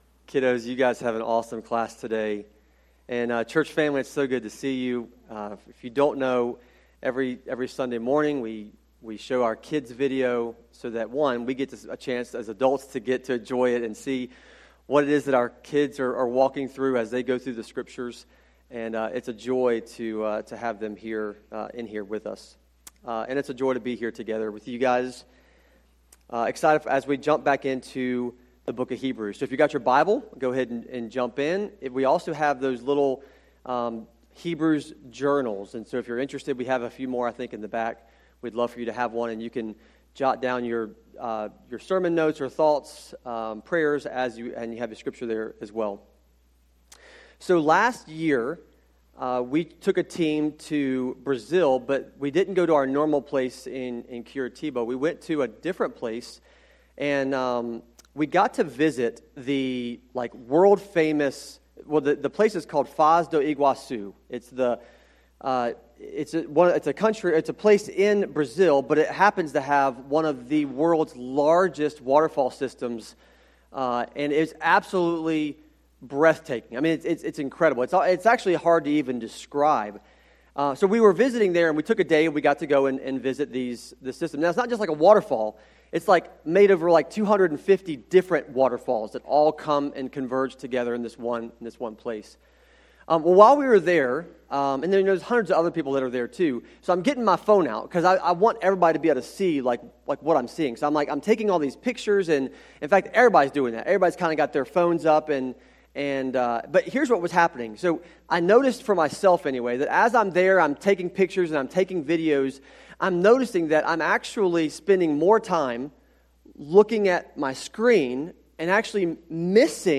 sermon-audio-trimmed-2.mp3